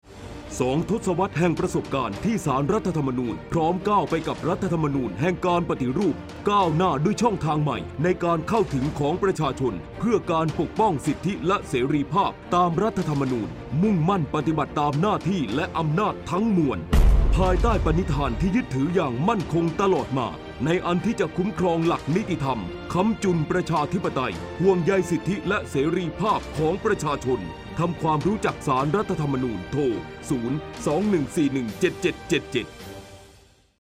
สปอตวิทยุประชาสัมพันธ์ศาลรัฐธรรมนูญ